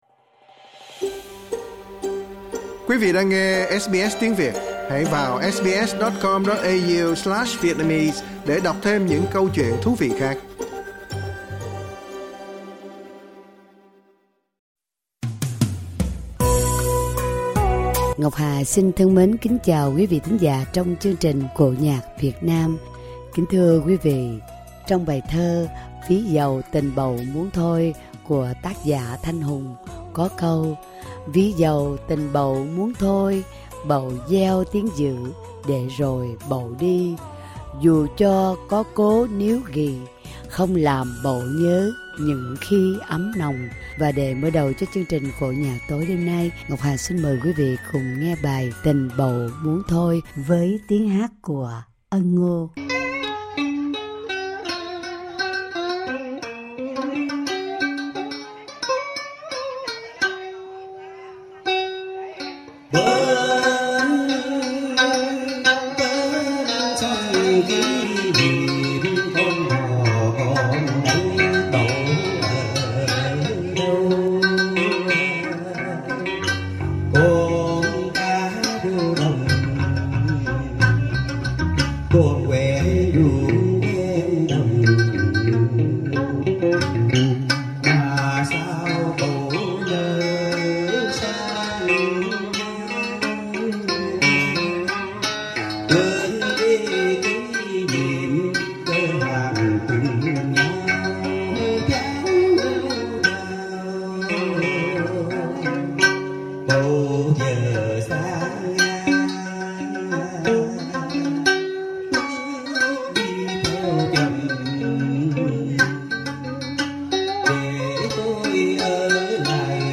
Cổ Nhạc Việt Nam: Trích đoạn cải lương Đào Hoa Khách